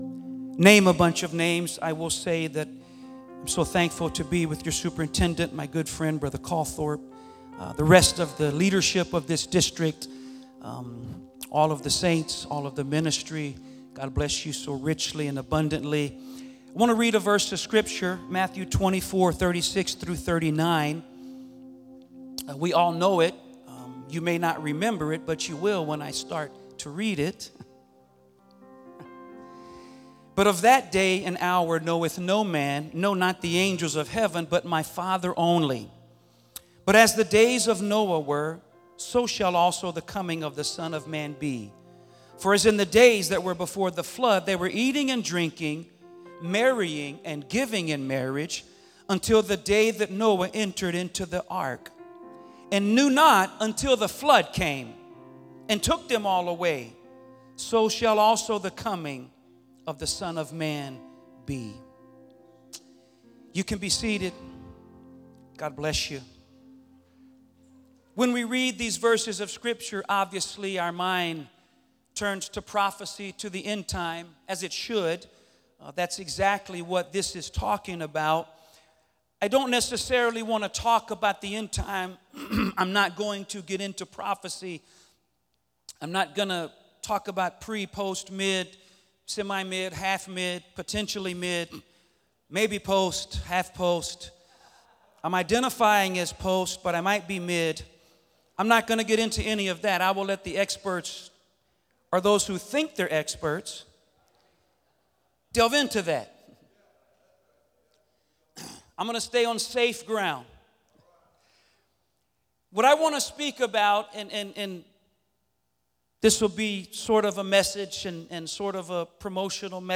Sermon Archive | Illinois District